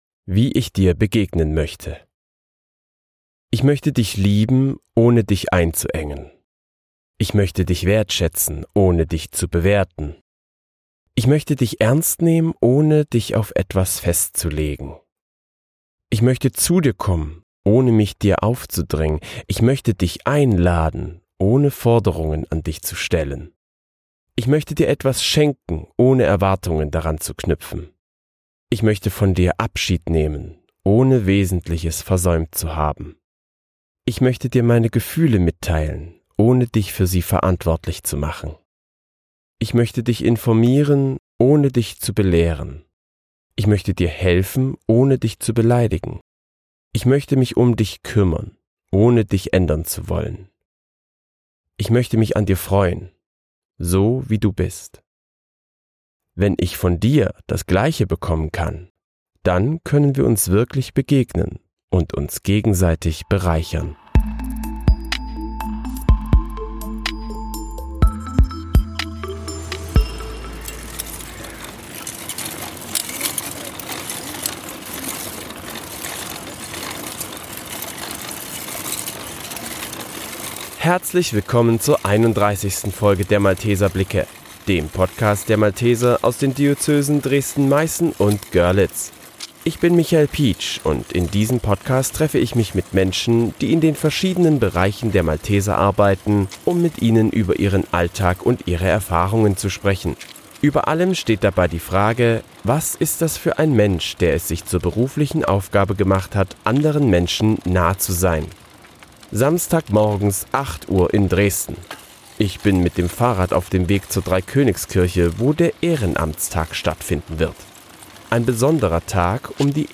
Vor allem steht dabei die Begegnung im Vordergrund. Alles das fand am 20.09.2025 in der Dreikönigskirche in Dresden statt. Wie bunt gemischt die Veranstaltung war und was es da alles zu entdecken gibt, das könnt ihr in dieser Folge hören.